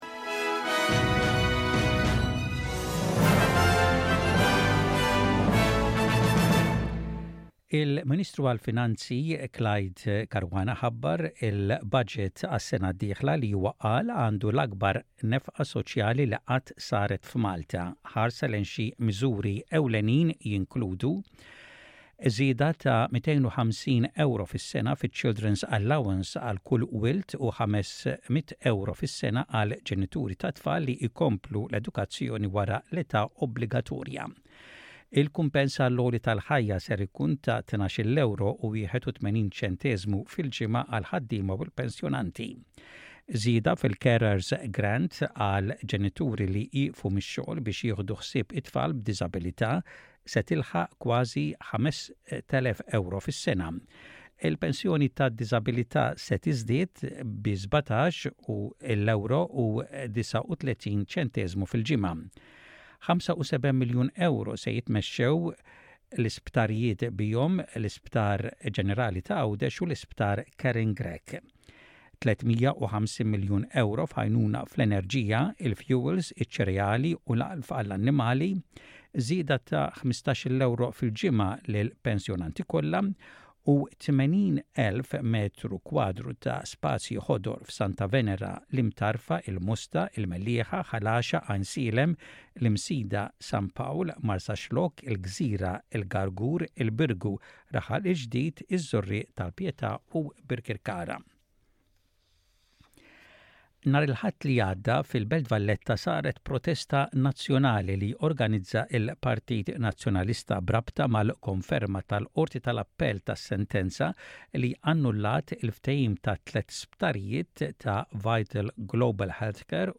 SBS Radio | Maltese News: 31.10.23